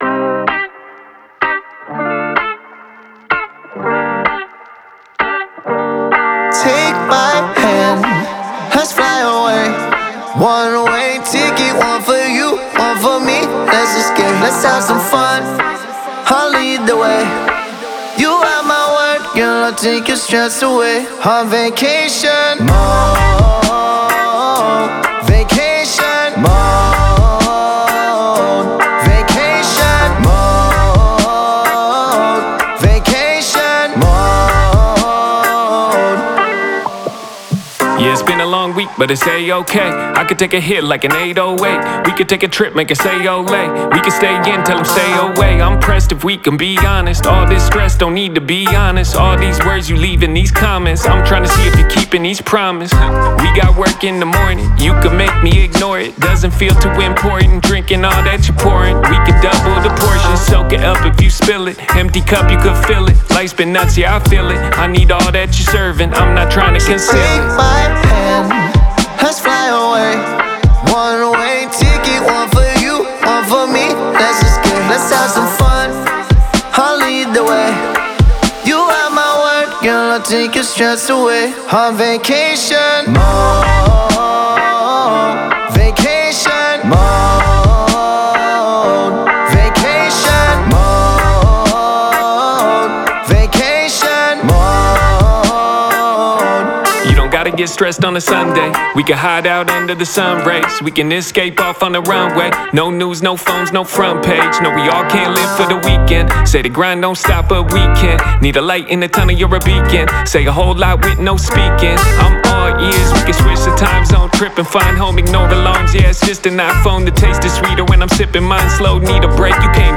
blending hip hop, reggae-rock, and alternative influences.